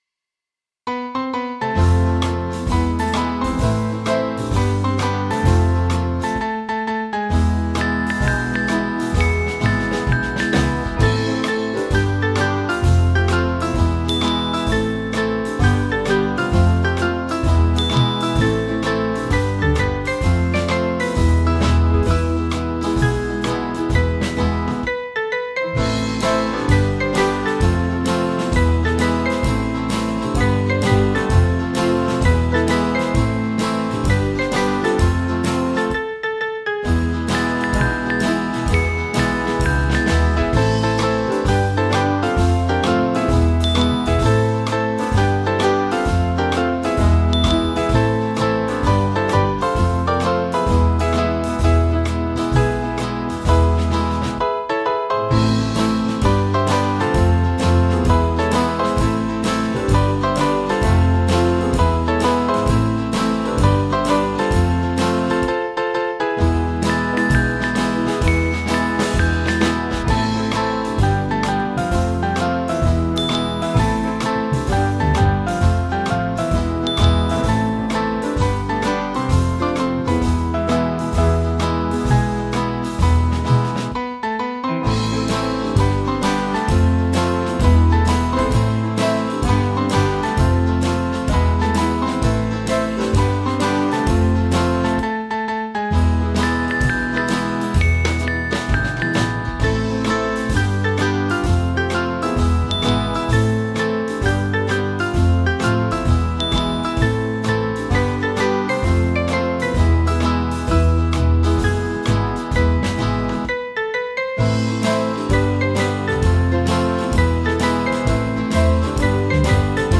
Swing Band
(CM)   5/Am